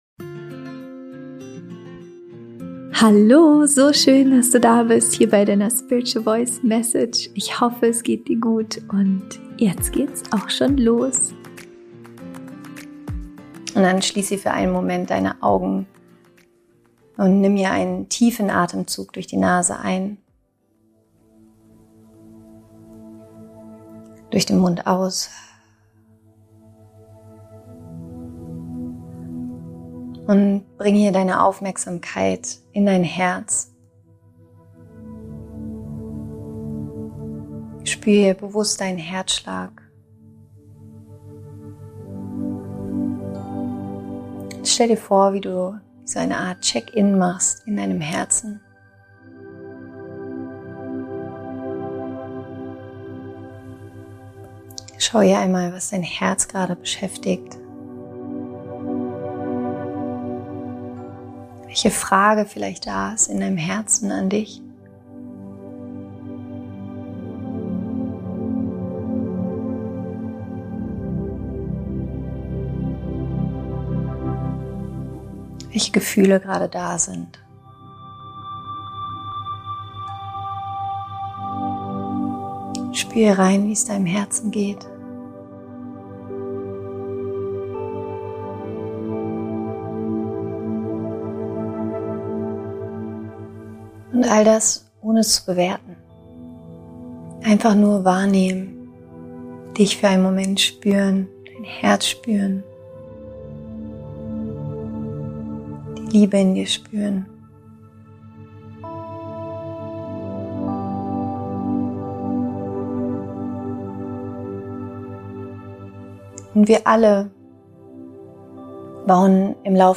Mini Meditation: Check-In in dein Herz | Dein Impuls für heute